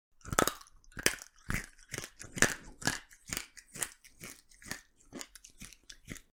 10. Грызут морковь как кролик